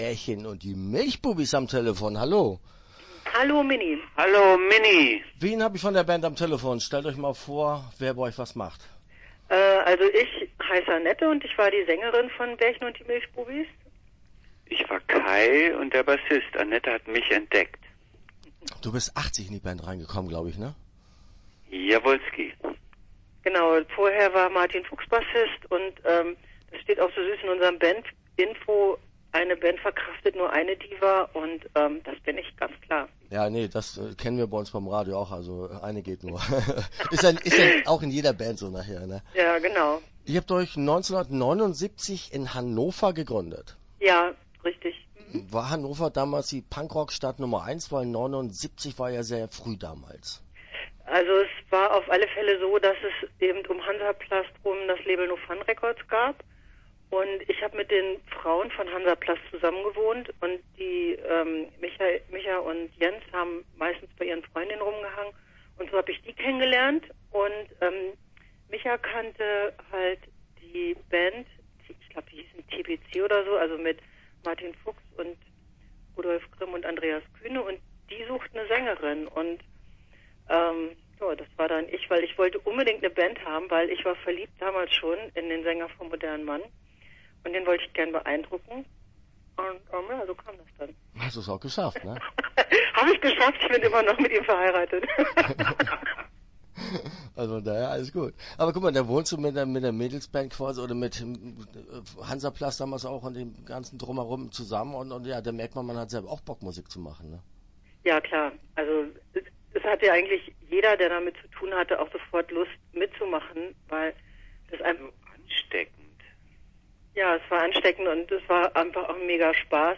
Start » Interviews » Bärchen und die Milchbubis